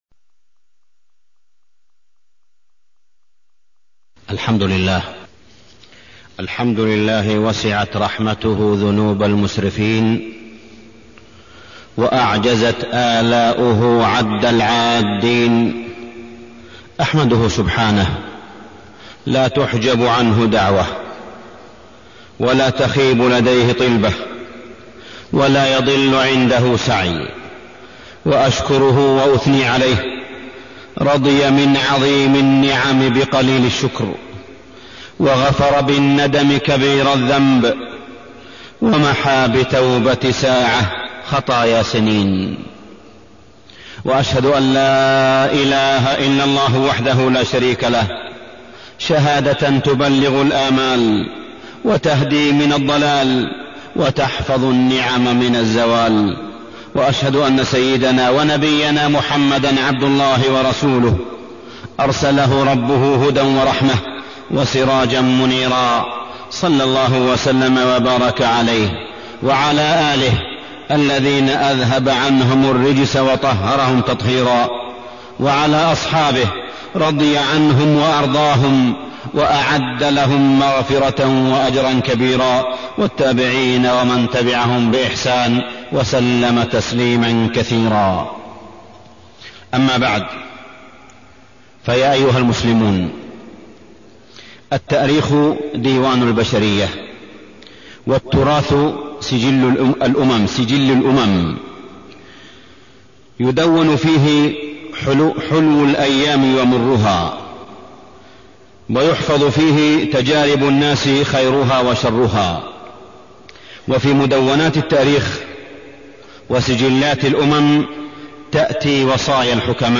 تاريخ النشر ١٧ شوال ١٤٢١ هـ المكان: المسجد الحرام الشيخ: معالي الشيخ أ.د. صالح بن عبدالله بن حميد معالي الشيخ أ.د. صالح بن عبدالله بن حميد وصايا و توجيهات